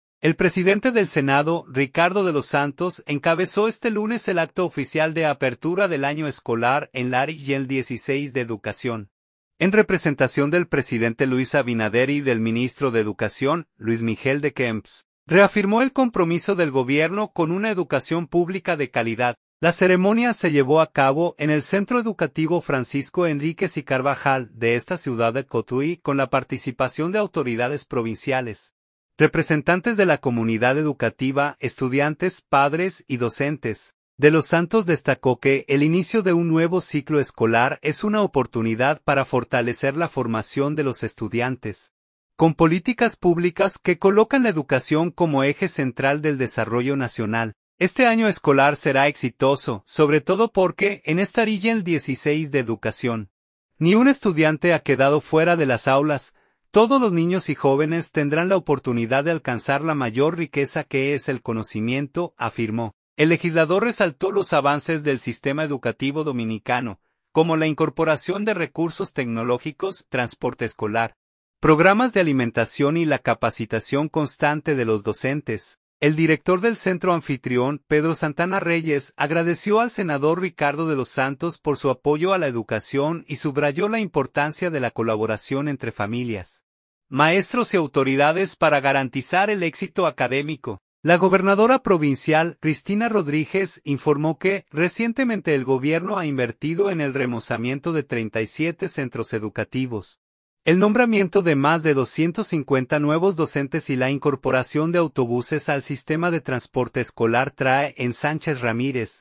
La ceremonia se llevó a cabo en el Centro Educativo “Francisco Henríquez y Carvajal”, de esta ciudad de Cotuí, con la participación de autoridades provinciales, representantes de la comunidad educativa, estudiantes, padres y docentes.